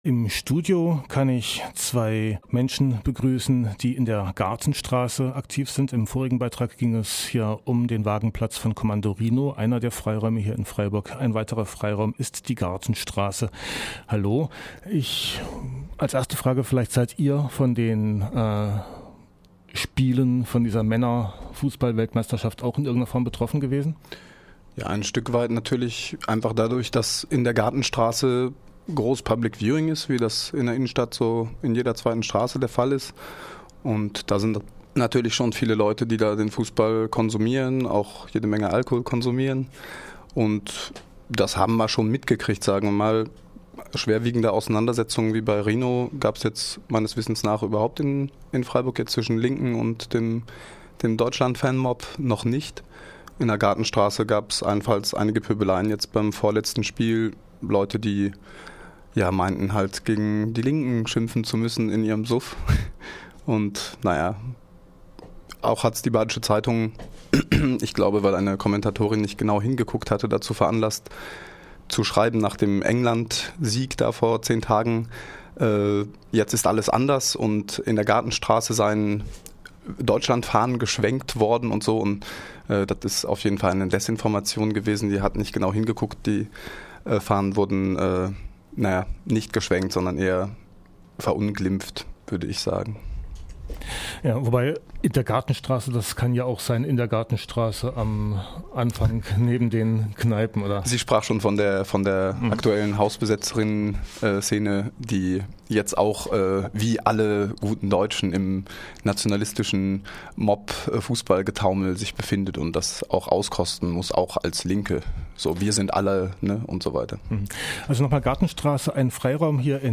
Seit 76 Tagen besetzt hat sich das Haus in der Gartenstr. 19 in Freiburg zu einem rege genutzten Kultur- und Kommunikationszentrum entwickelt... In Punkt12 sprachen wir mit zwei Aktivisten aus dem Freiraum Gartenstrasse 19